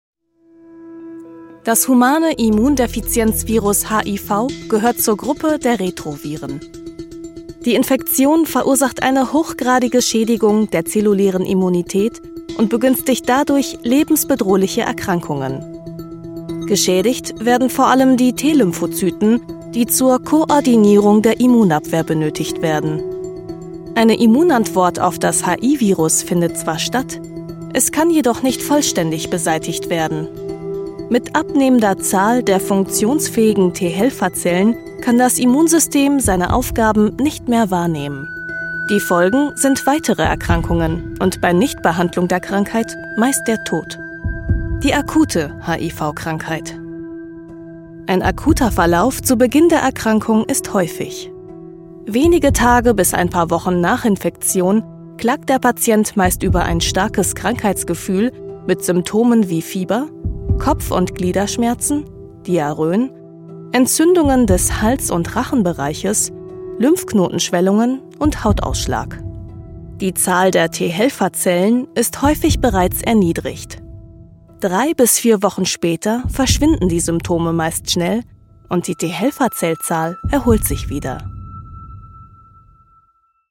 Professionelle Sprecherin mit eigenem Studio.
Kein Dialekt
Sprechprobe: Sonstiges (Muttersprache):